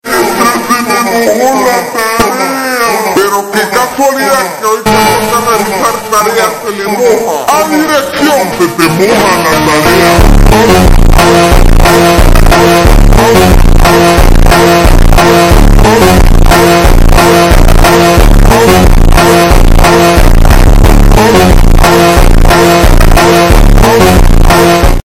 slowed
phonk